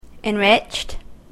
/ɛˈnrɪtʃt(米国英語), eˈnrɪtʃt(英国英語)/